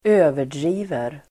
Uttal: [²'ö:ver_dri:ver]